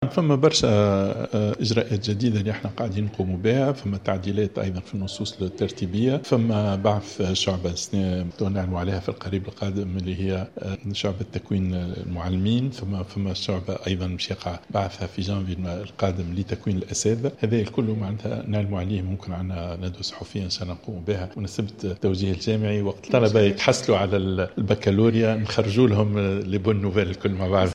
و أوضح في تصريح صحفي على هامش اختتام المسابقة الوطنية في التجديد والتشجيع التي أقيمت عشية السبت بالمعهد العالي للدراسات التكنولوجية بقليبية تحت شعار "طلبة مبادرون..مبدعون"، أن من بين الشعب الجديدة شعبة تكوين المعلمين بالإضافة إلى شعبة لتكوين الأساتذة سيعلن عنها في جانفي 2017.